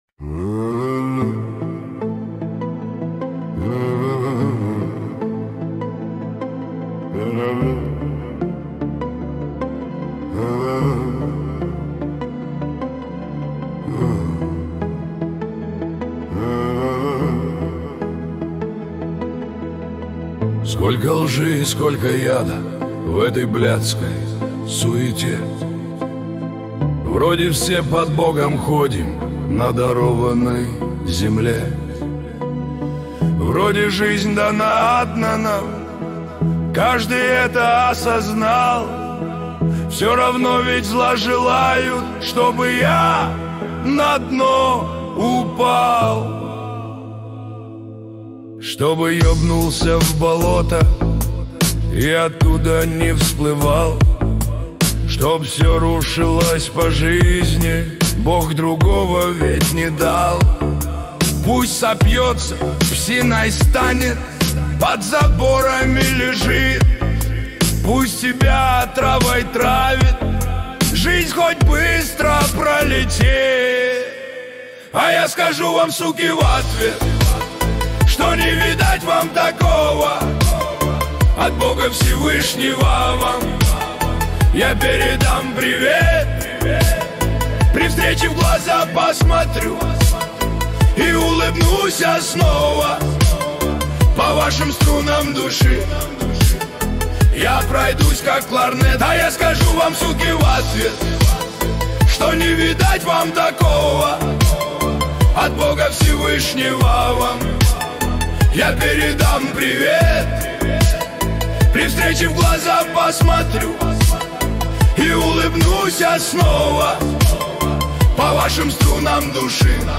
Качество: 226 kbps, stereo
Нейросеть Песни 2025